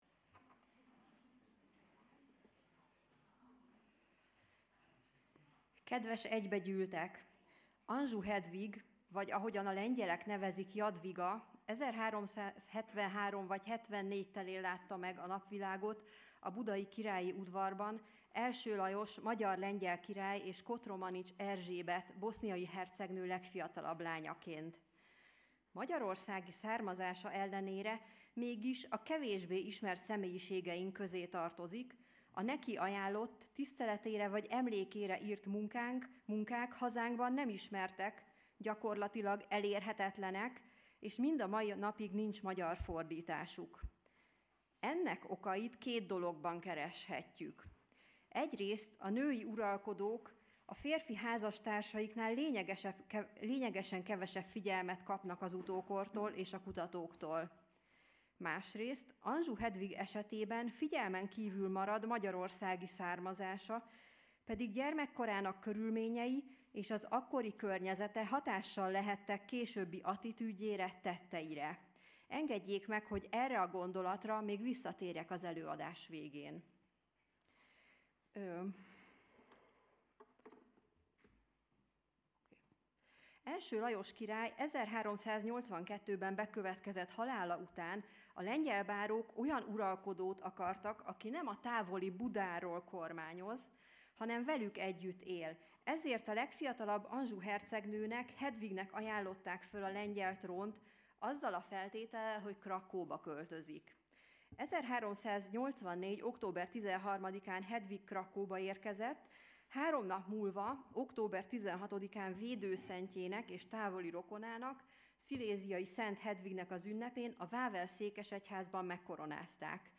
Ötödik ülés
(lecturer)